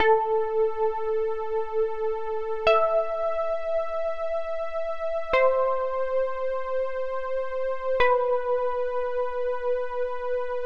描述：寒意垫午夜版2
Tag: 寒意 半夜